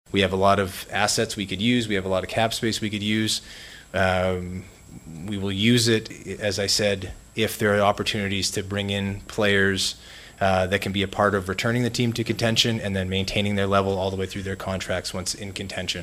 Penguins president of hockey operations Kyle Dubas took questions for over half an hour yesterday in his season-ending news conference, and while he is certain he has the team on the right track, he hinted it might be another year before fans see the sort of Penguins Stanley Cup-contending team they are used to.